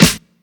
Snare MadFlavor 2.wav